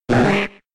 Cri de Ramoloss K.O. dans Pokémon X et Y.